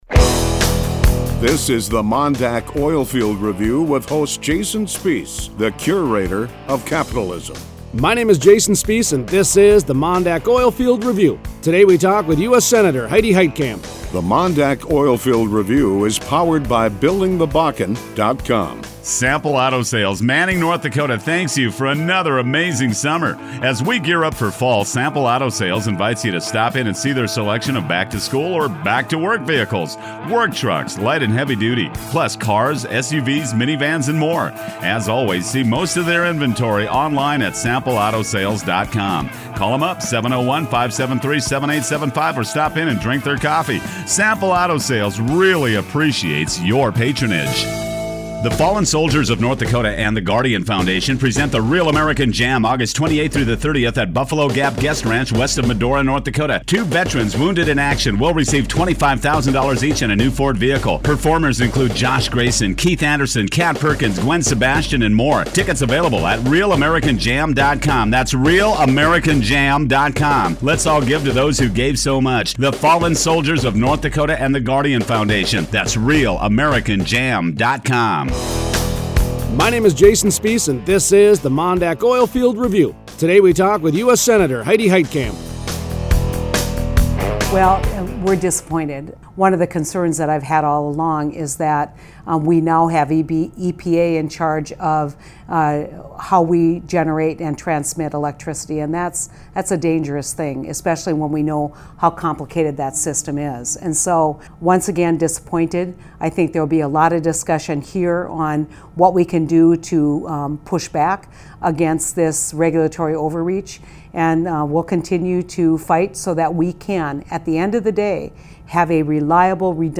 Monday 8/10 Interview: US Senator Heidi Heitkamp (ND-D).